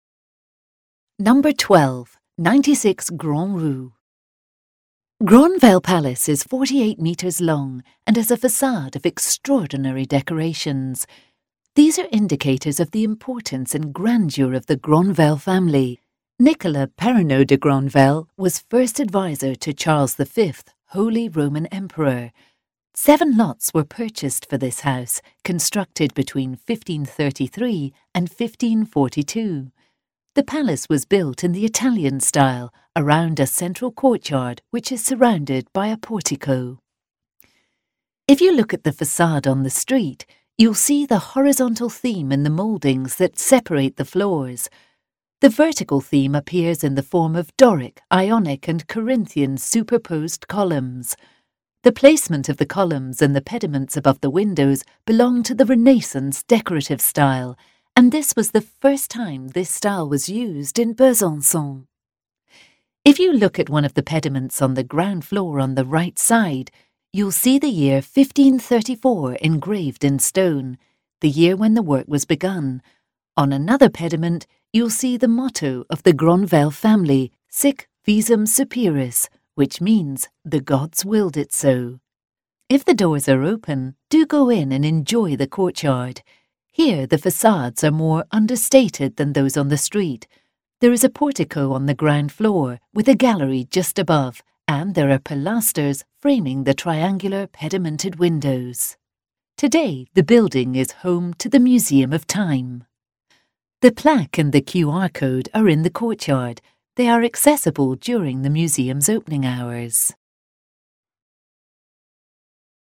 Ecouter l'audio guide